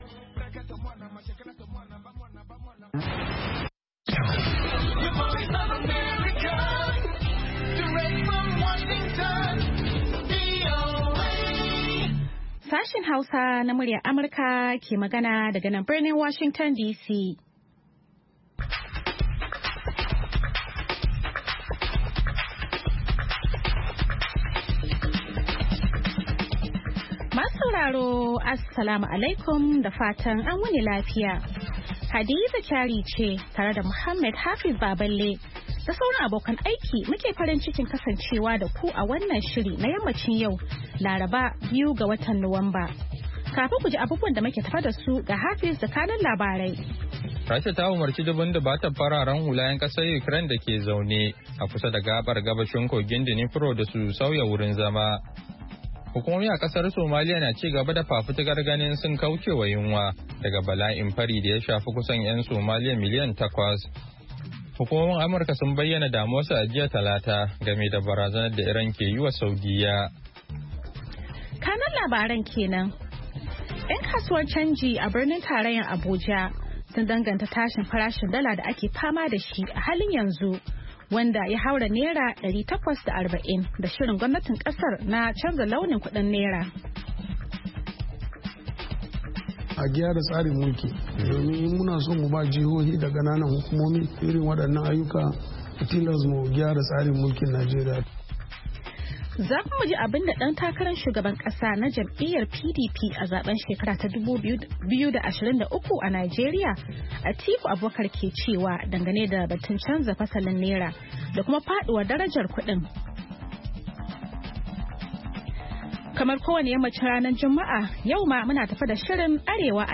Da karfe 4 na yamma agogon Najeriya da Nijar zaku iya jin rahotanni da labarai da dumi-duminsu daga kowace kusurwa ta duniya, musamman ma dai muhimman abubuwan da suka faru, ko suke faruwa a kusa da ku a wannan rana.